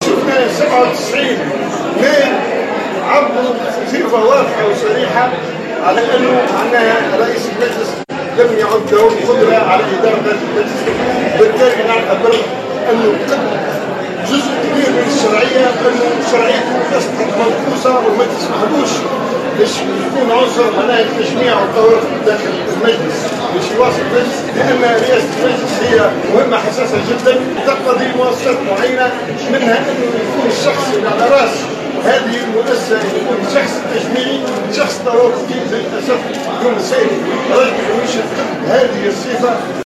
وأشار بن أحمد خلال ندوة صحفية عقدتها الكتلة الديمقراطية بالمجلس، (صاحبة مبادرة سحب الثقة من رئيس البرلمان)، إلى أنّ الغنوشي لم يعد شخصية توافقية قادرة على التجميع في المؤسسة التشريعية، خاصة بعد تصويت 97 نائبا لسحب الثقة منه.